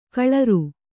♪ kaḷaru